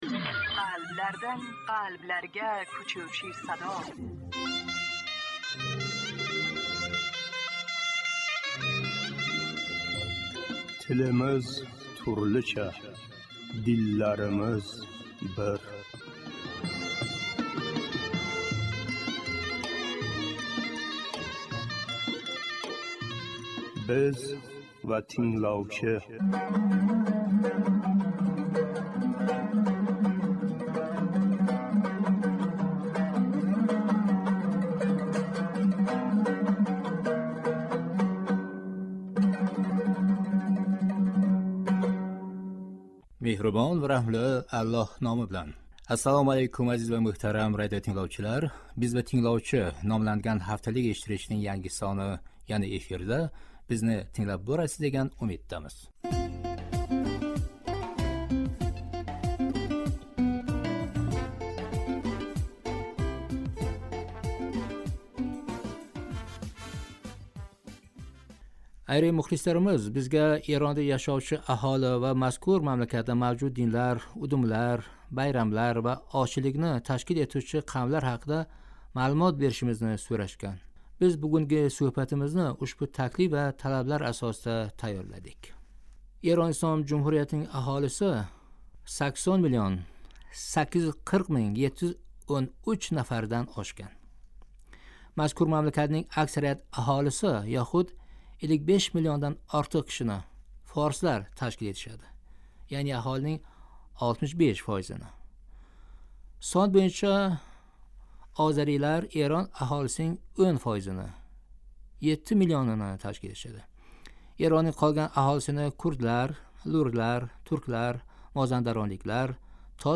Тингловчилар сўҳбати